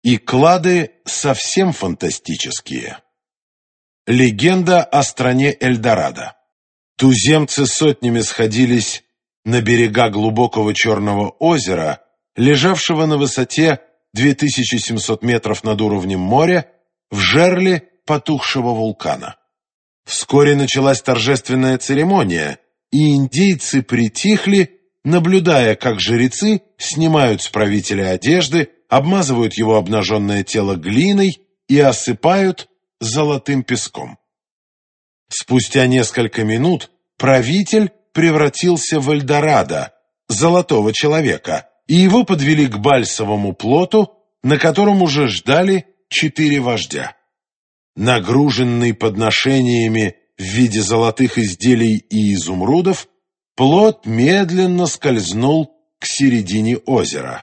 Аудиокнига Легендарные клады | Библиотека аудиокниг